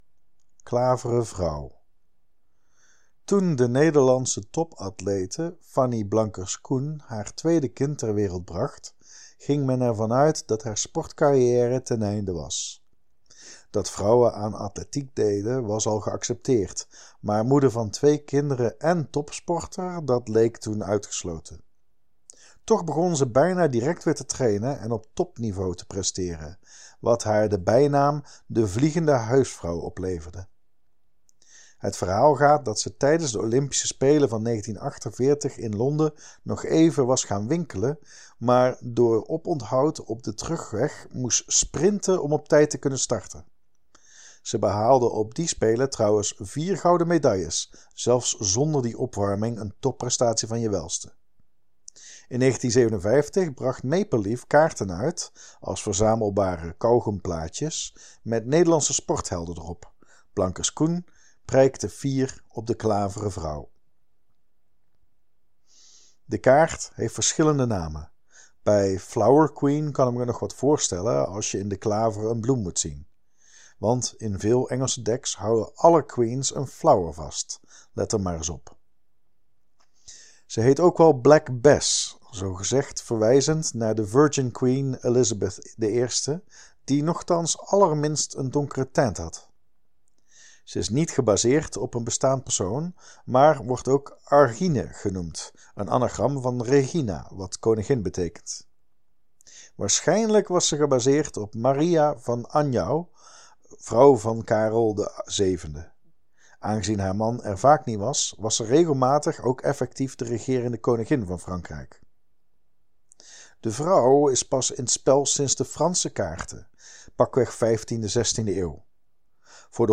04.12b-Klaveren-vrouw-toelichting.mp3